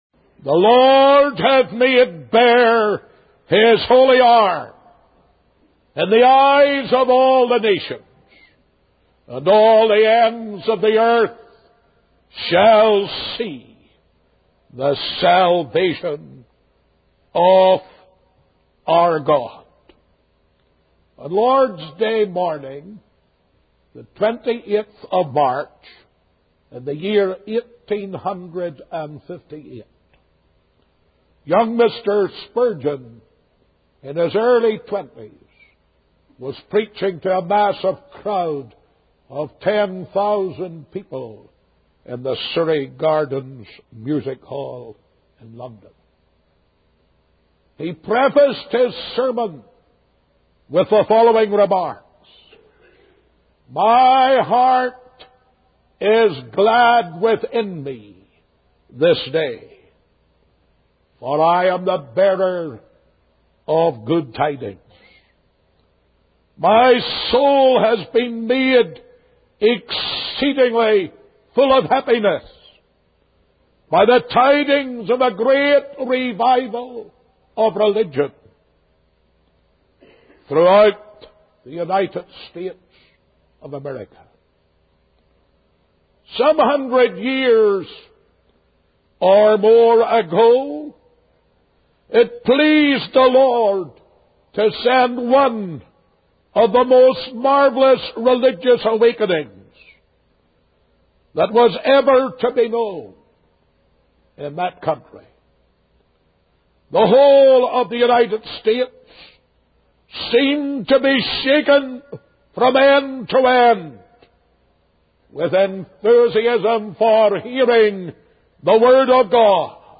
In this sermon, the preacher emphasizes the power and sovereignty of God in bringing about revival. He emphasizes that revival is initiated, controlled, and concluded by the Lord, and no human effort can stop or continue it.